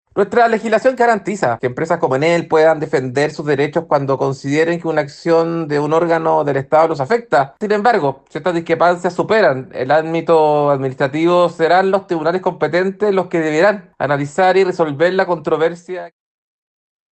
El diputado de Renovación Nacional e integrante de la Comisión de Minería y Energía, Andrés Celis, señaló en declaraciones a Radio Bío Bío que la ley garantiza que las empresas puedan defender sus derechos en este tipo de situaciones.